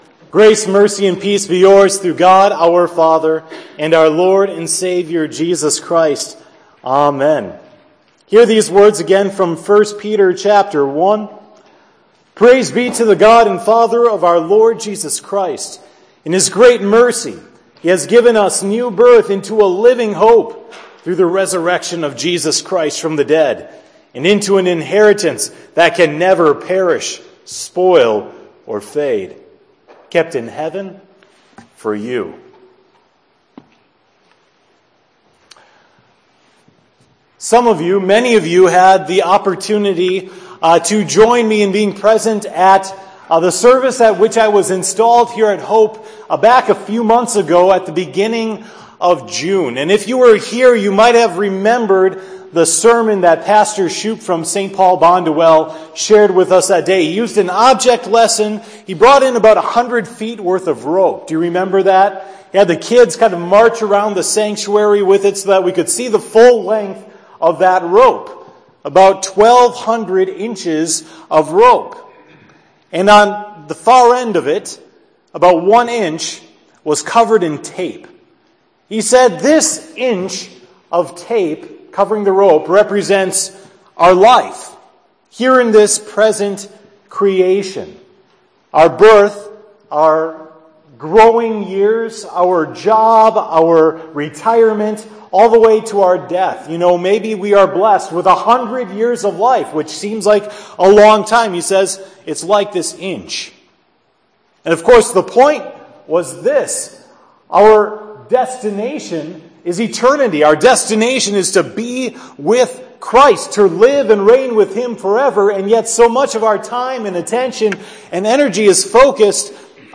“BELIEVE: Hope” – Sermon for 10/16